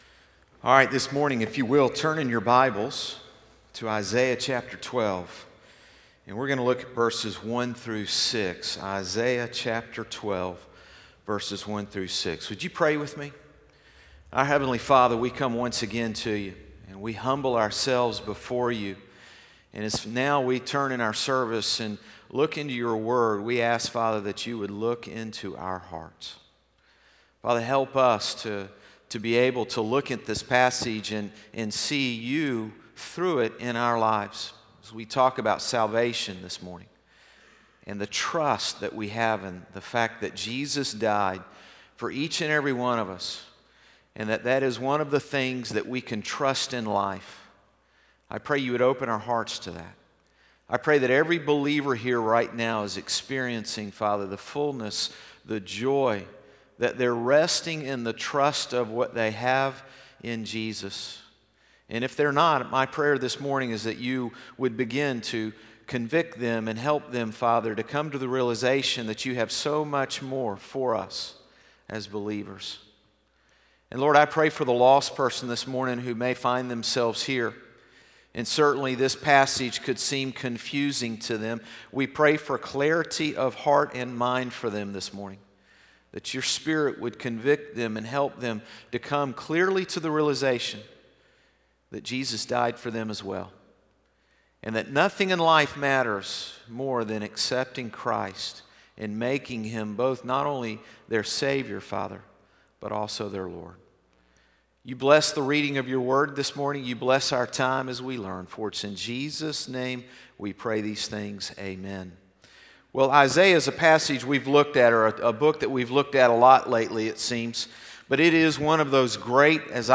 Sermons - Concord Baptist Church
Morning Service 8-26-18.mp3